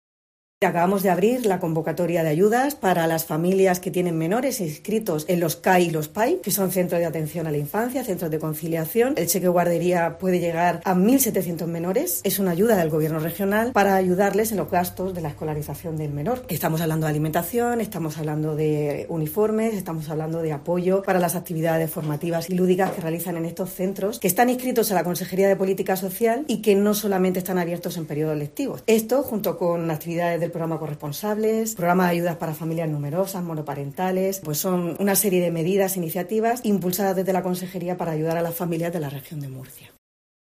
Conchita Ruiz, consejera de Política Social, Familias e Igualdad